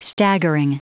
Prononciation du mot staggering en anglais (fichier audio)
Prononciation du mot : staggering